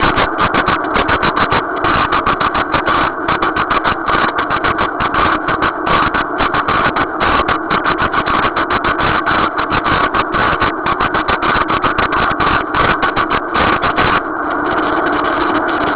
The following table has some recordings I made from the event.